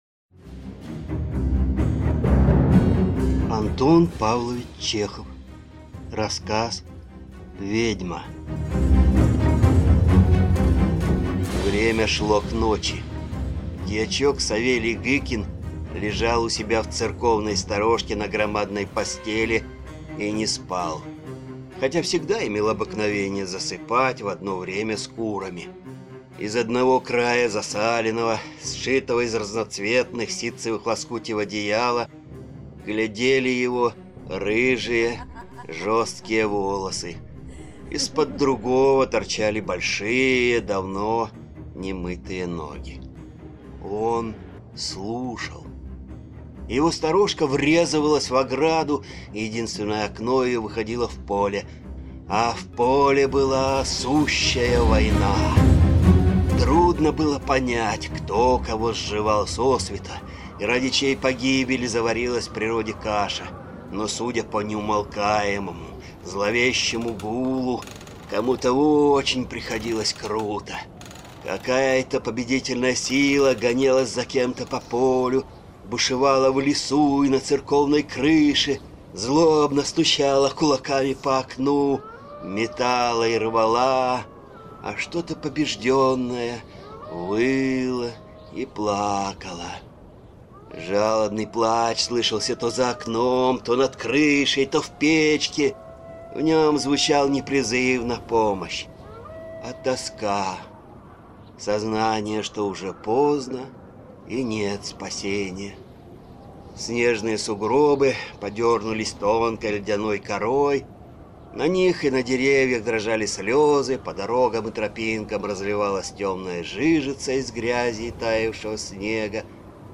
Аудиокнига Ведьма | Библиотека аудиокниг